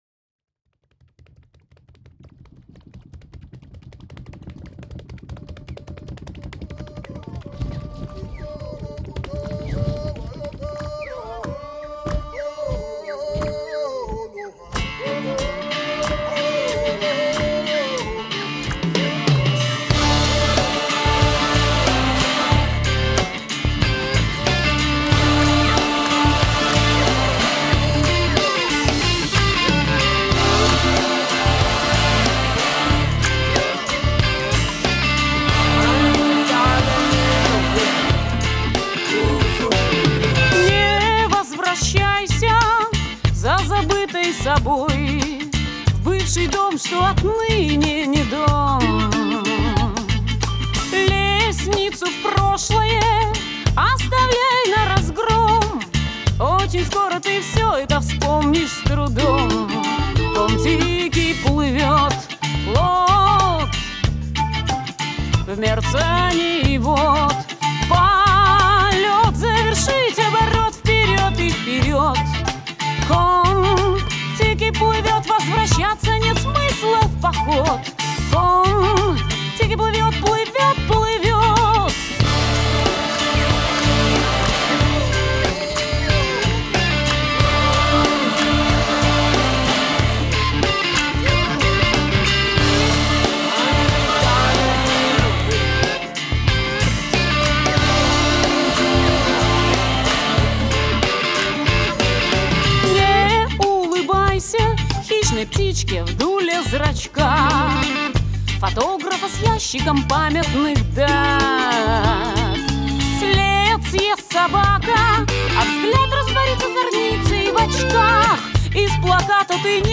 Студийный электрический альбом.
бас-гитара
клавиши
гитара
ударные, перкуссия